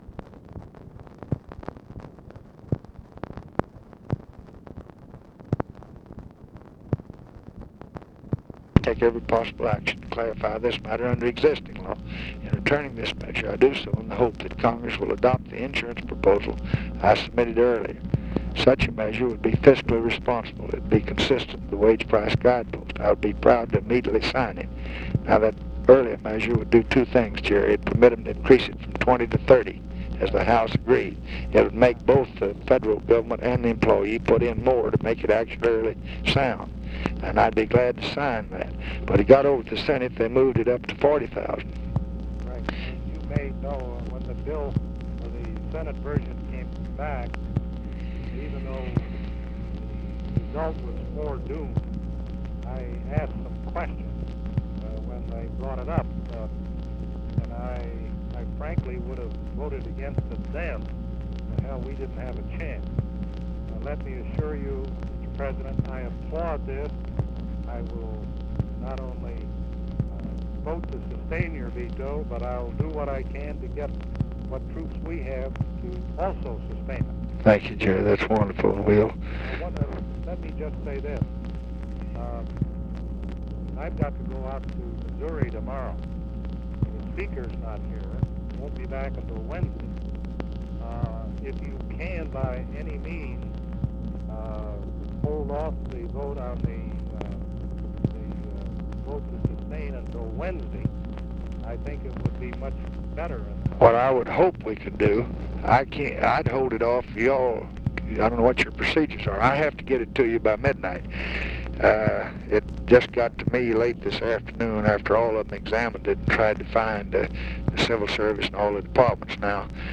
Conversation with GERALD FORD, September 13, 1966
Secret White House Tapes